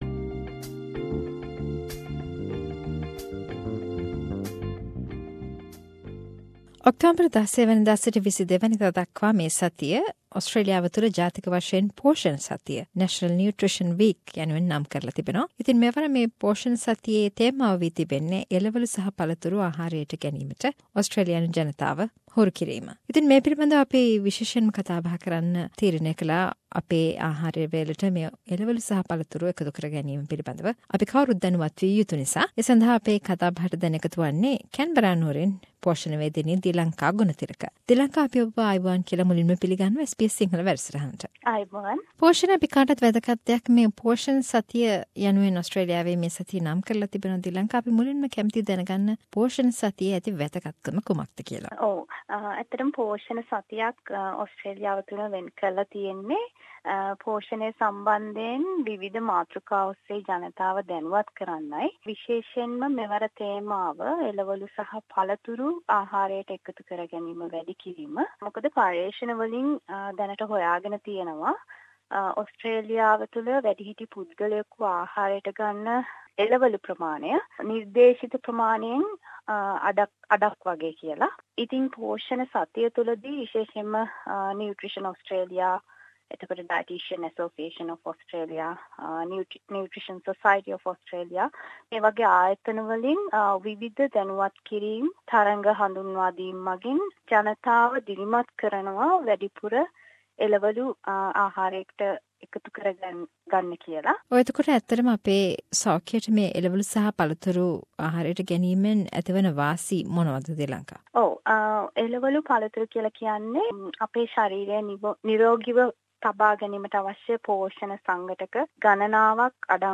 A discussion with nutritionist